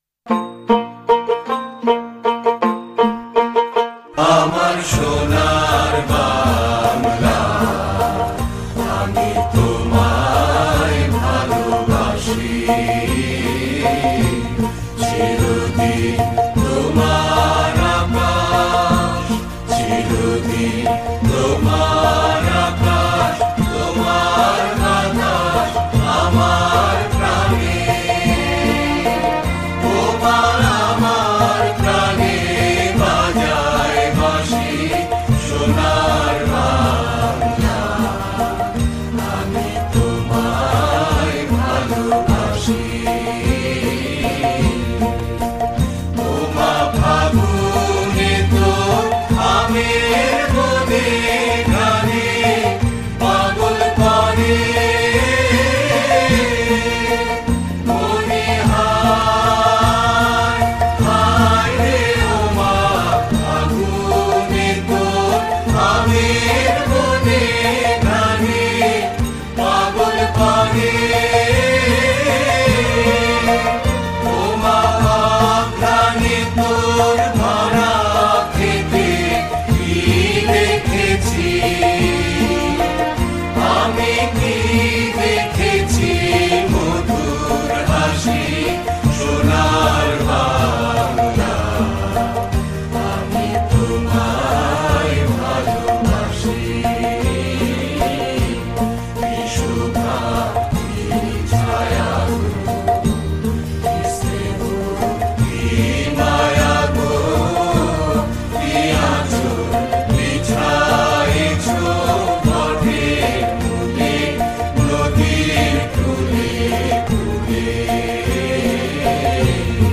с текстом